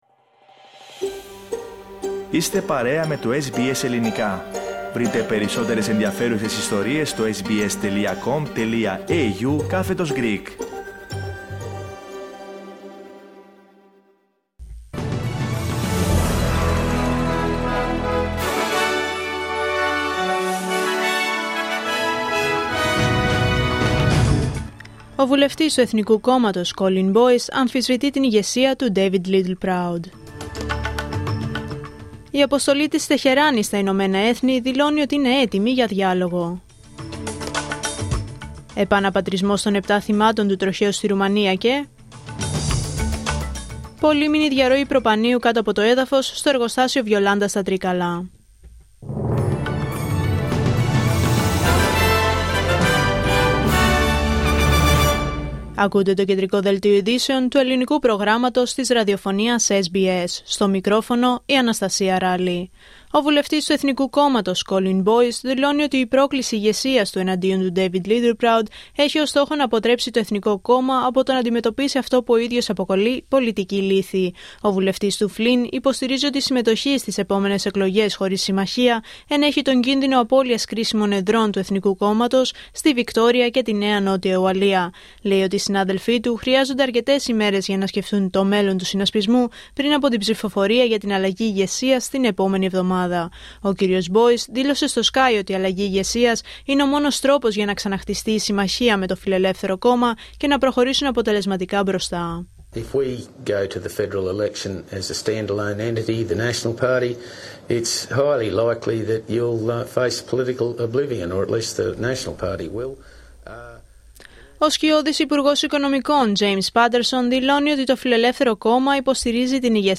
Δελτίο Ειδήσεων Πέμπτη 29 Ιανουαρίου 2026